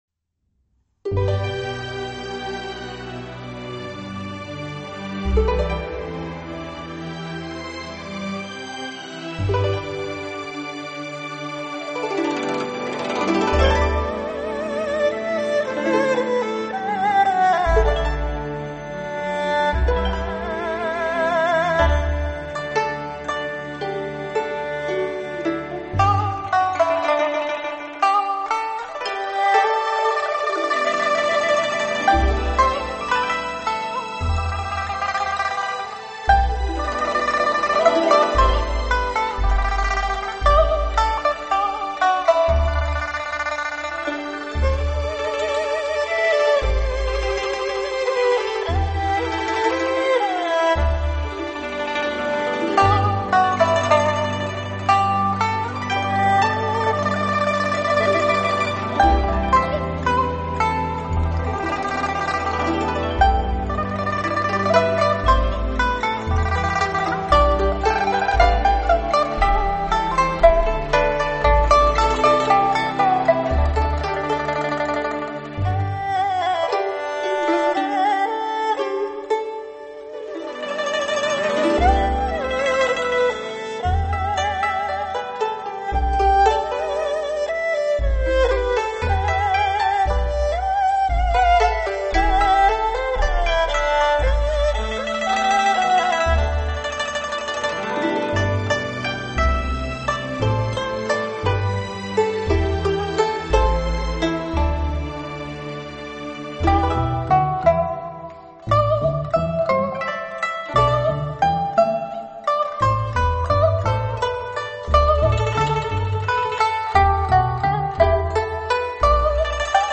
类 别：DSD